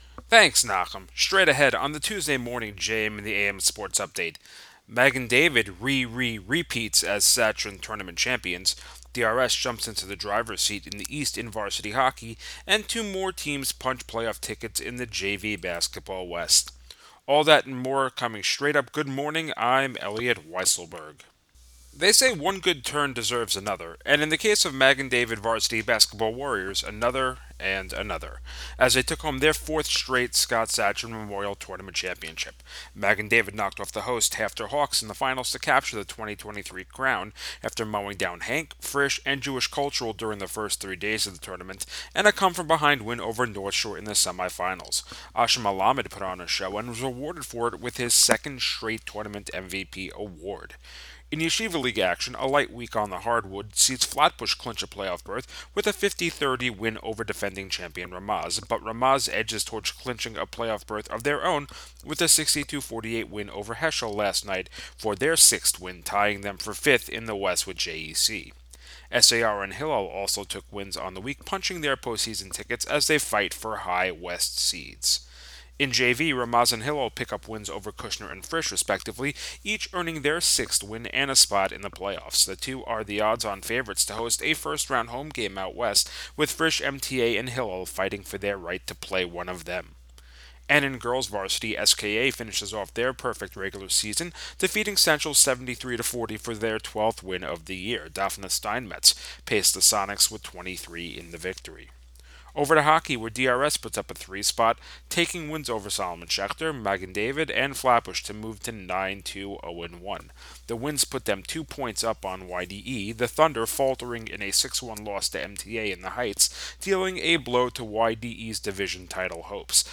News, Sports